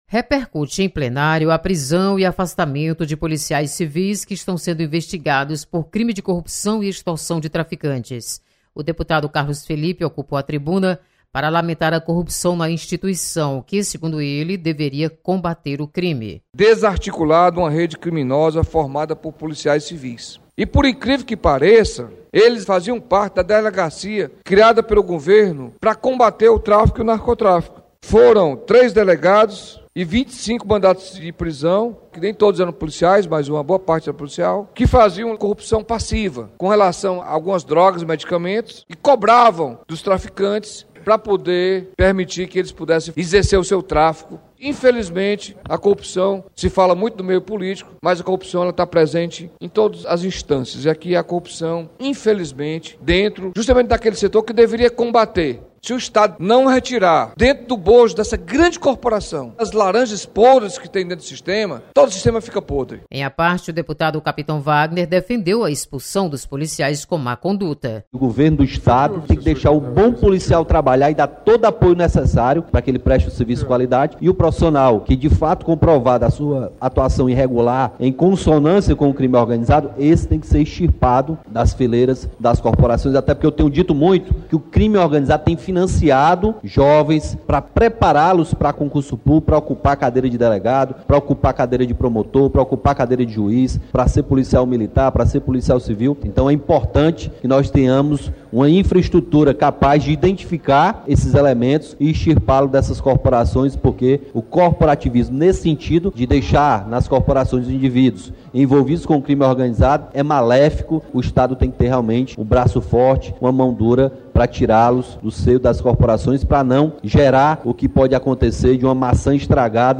Você está aqui: Início Comunicação Rádio FM Assembleia Notícias Polícia